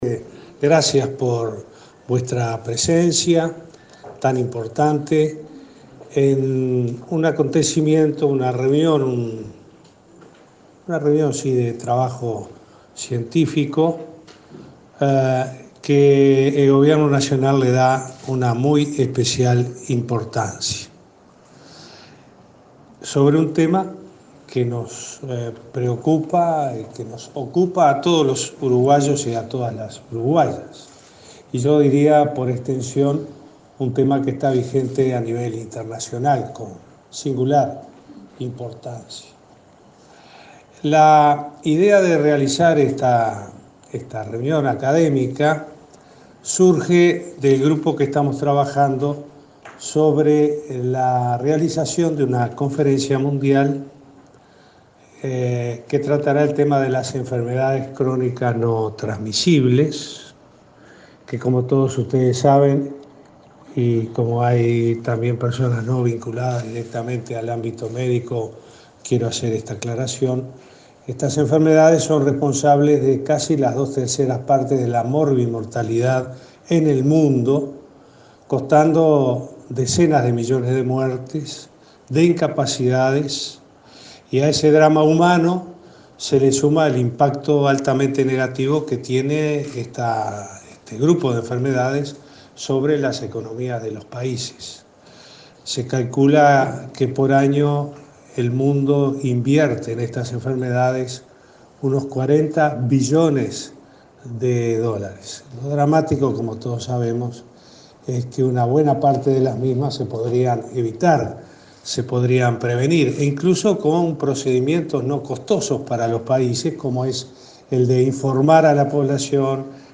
El presidente Tabaré Vázquez sostuvo que es importante que la academia colabore y fundamente los riesgos del consumo y las propiedades que eventualmente el cannabis y sus productos puedan tener sobre el tratamiento de algunas afecciones que los médicos vemos periódicamente. Vázquez participó en el foro “Evidencia y riesgos sobre la utilización del cannabis”, organizado por el MSP en Torre Ejecutiva.